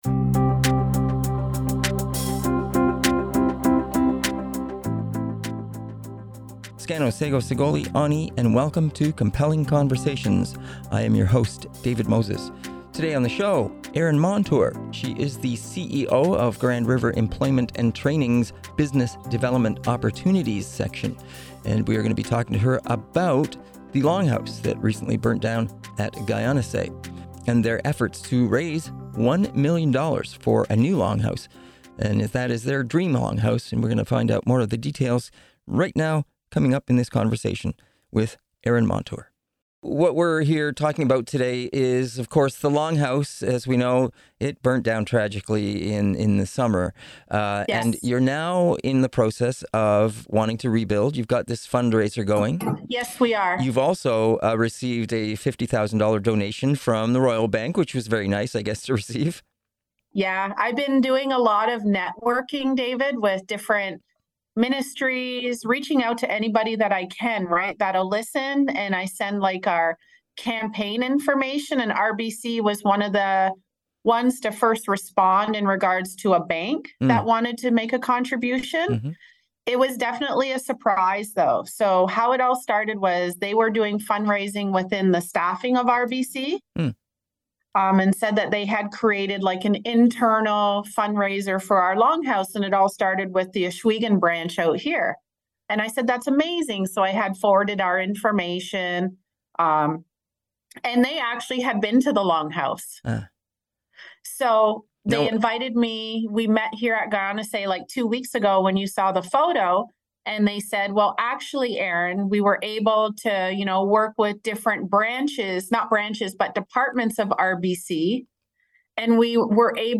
Listen to the entire interview below: Download Audio Prev Previous Post Water research project hopes VR experience will engage youth on water issues Next Post West Kootenay water advocates head to international climate conference Next